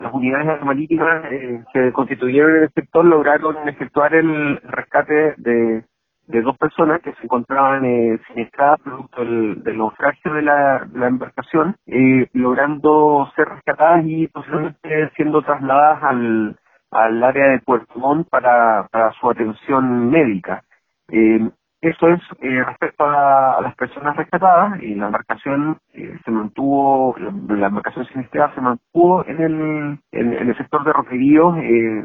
Una vez detectado el lugar del accidente y de las dos personas, la Armada decidió desplegar un operativo aéreo con un equipo especializado, explicó el capitán de Puerto de Maullín, Ricardo Cartes.